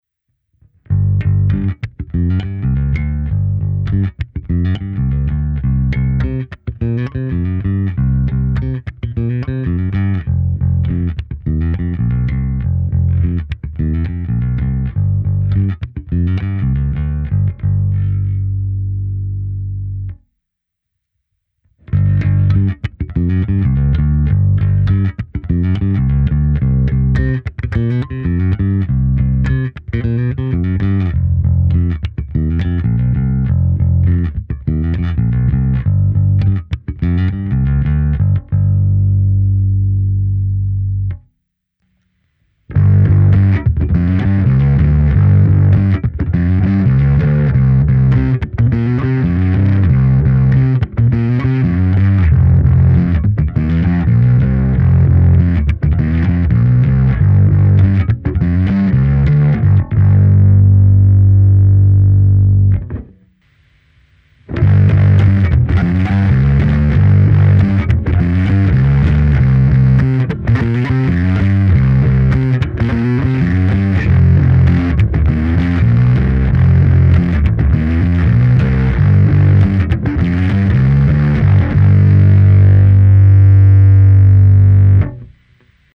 Zkreslení lze nastavit od lehkého nakřápnutí až po skoro fuzz, v každém ohledu má ale spíš vintage charakter.
Nahrál jsem ukázky s baskytarou Fender American Professional II Precision Bass V s roundwound niklovými strunami Sadowsky Blue Label v dobrém stavu. V nahrávkách jsem použil vždy kompresor, lehkou ekvalizaci a simulaci aparátu snímaného kombinací linky a mikrofonu. V první části je jen zvuk baskytary bez zařazení pedálu, v druhé části je zvuk s použitím sekce Clean Boost, následuje nižší varianta zkreslení a končí to variantou výraznějšího zkreslení.